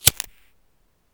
lighter.wav